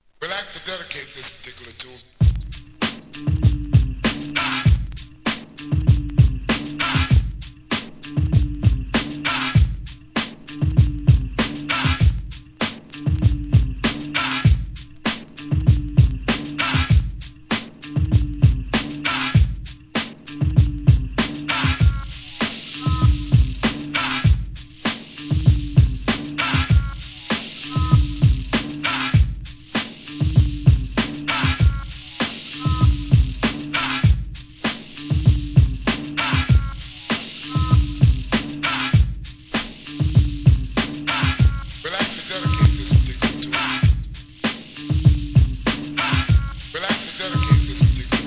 HIP HOP/R&B
ブレイクビーツEP!!